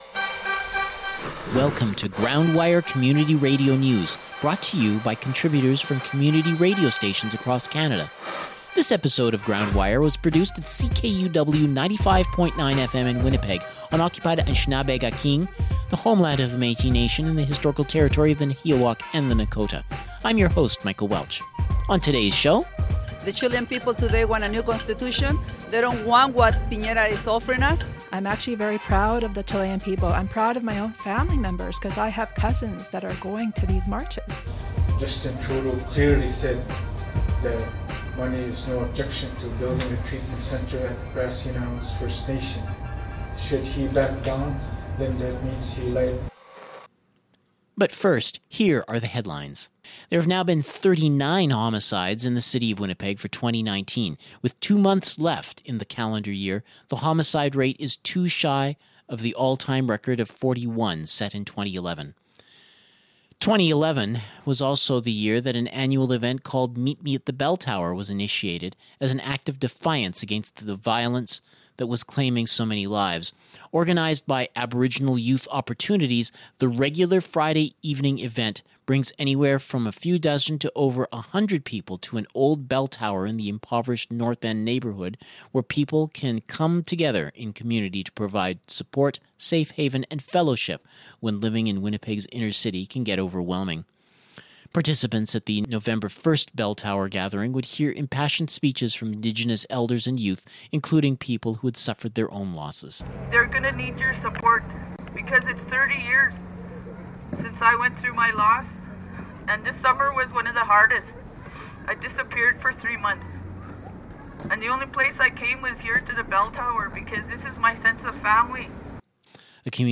Community radio news from coast to coast to coast